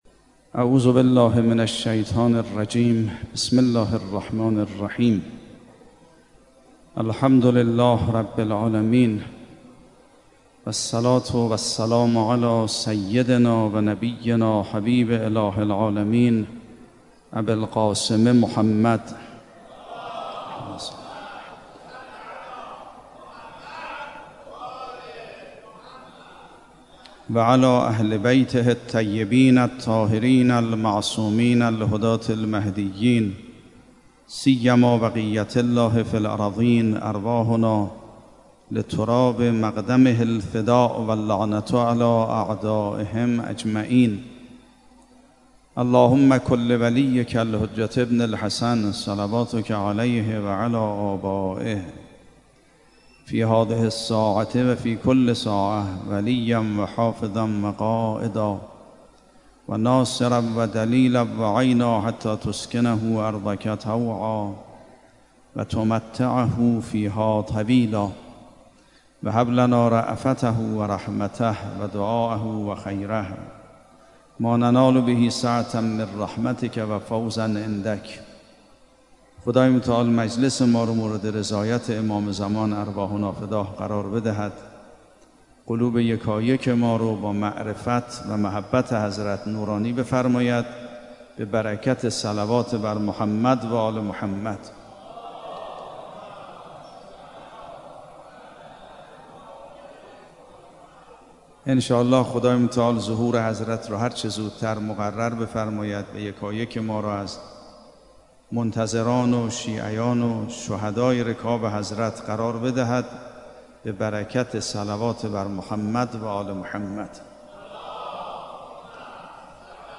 به گزارش سرویس سیاسی خبرگزاری رسا، آیت‌الله سید‌مهدی میرباقری طی سخنانی در چهارمین شب محرم بعد از نماز عشاء در شبستان امام‌خمینی(ره) حرم‌بانوی‌کرامت گفت: حضرت در خطابه خودشان به هنگام خروج از مکه به سمت عراق، از صحنه‌های سخت و نحوه شهادت خودشان سخن به میان می‌آورند اما در عین حالی که شهادت سخت در انتظار حضرت است اما حضرت با کمال اشتیاق به این سفر می‌روند.